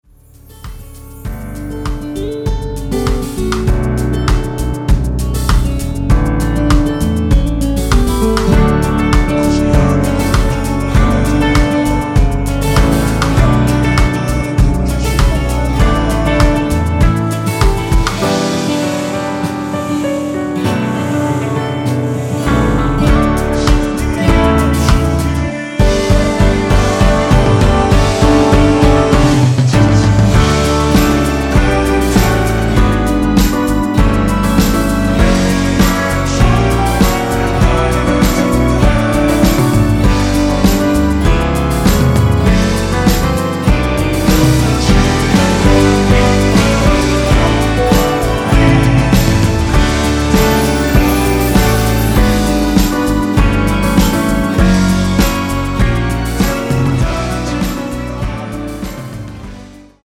원키에서(-6)내린 코러스 포함된 MR입니다.(미리듣기 확인)
앞부분30초, 뒷부분30초씩 편집해서 올려 드리고 있습니다.